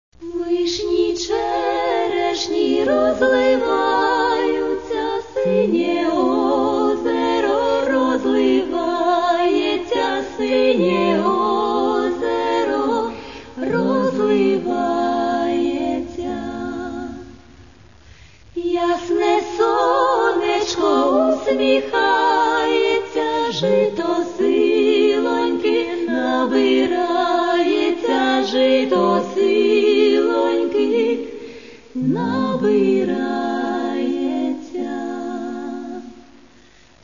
Каталог -> Народна -> Традиційне виконання